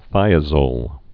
(thīə-zōl)